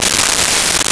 18 Wheeler Air Brakes 02
18 Wheeler Air Brakes 02.wav